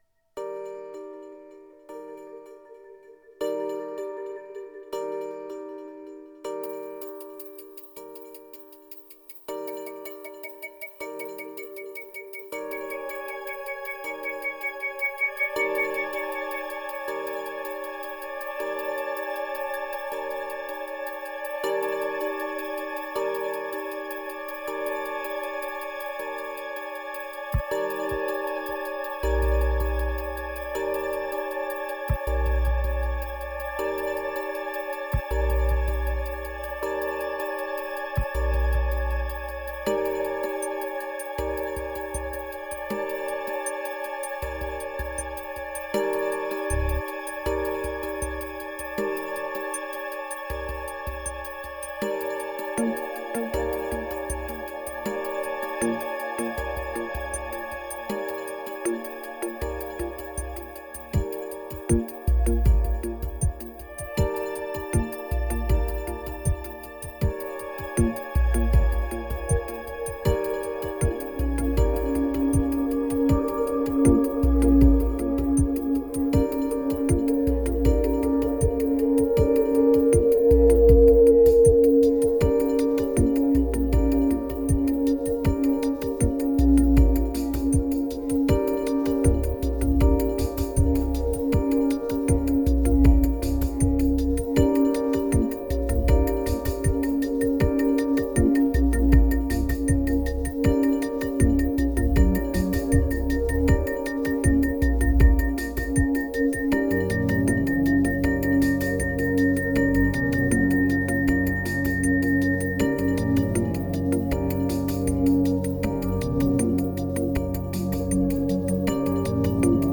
2146📈 - 11%🤔 - 79BPM🔊 - 2015-02-16📅 - -145🌟